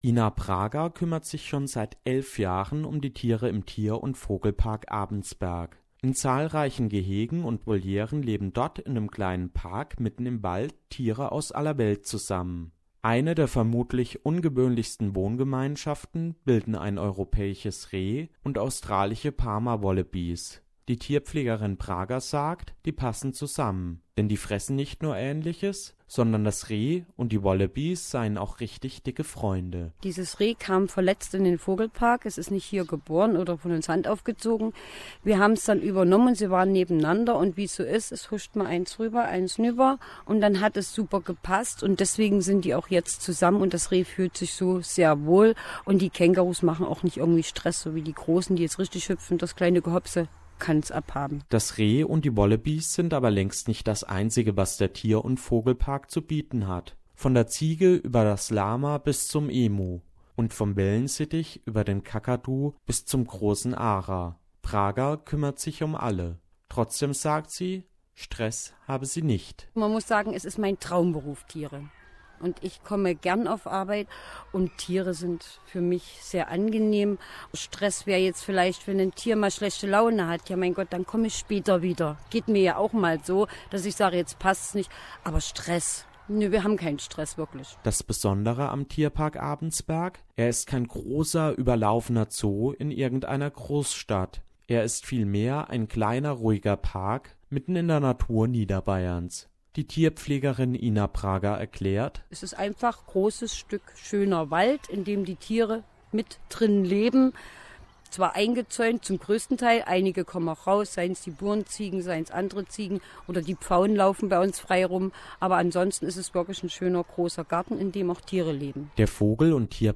SBS reporter